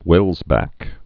(wĕlzbăk, -bäk)